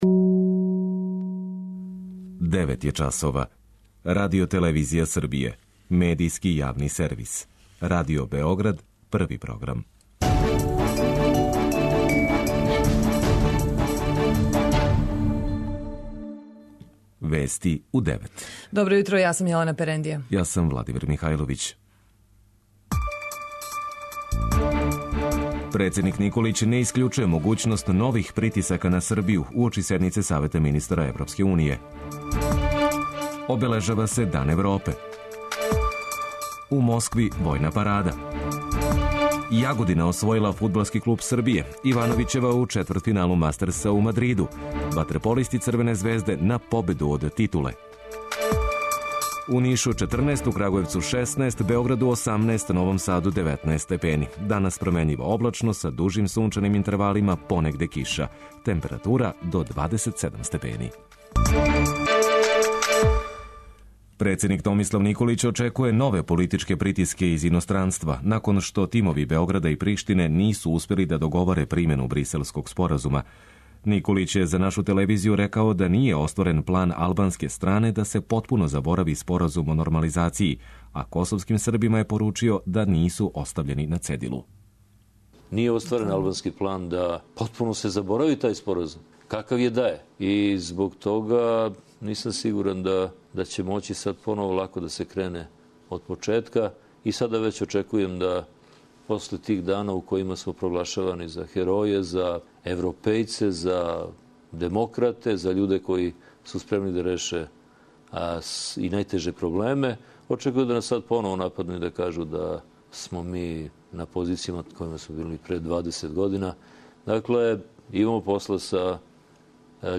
преузми : 8.64 MB Вести у 9 Autor: разни аутори Преглед најважнијиx информација из земље из света.